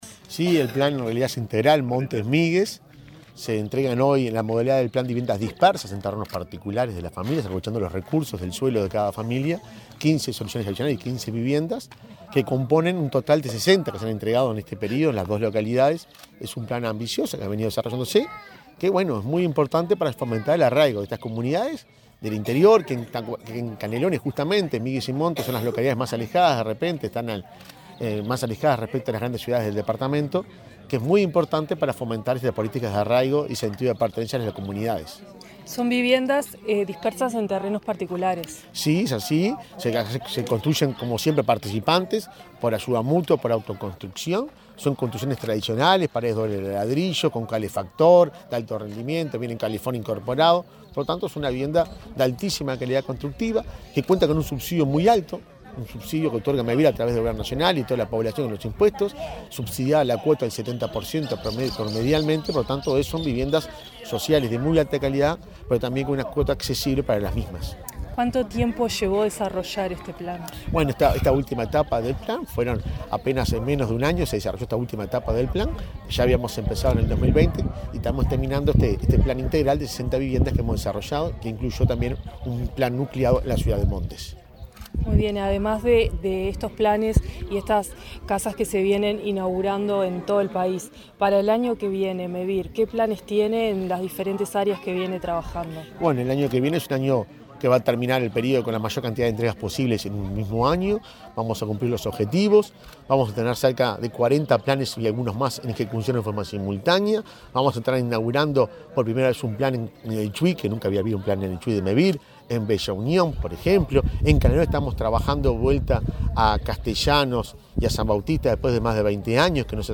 Entrevista al presidente de Mevir, Juan Pablo Delgado
Entrevista al presidente de Mevir, Juan Pablo Delgado 30/11/2023 Compartir Facebook X Copiar enlace WhatsApp LinkedIn El presidente de Mevir, Juan Pablo Delgado, dialogó con Comunicación Presidencial en Canelones, durante la entrega de 15 viviendas en la localidad de Montes.